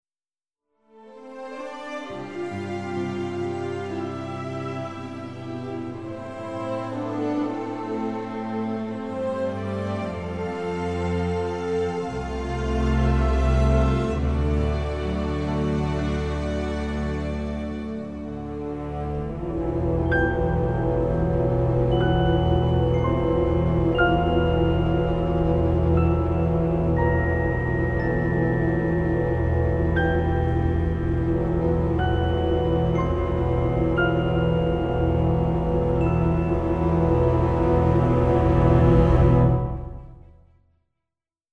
ORIGINAL FILM SOUNDTRACK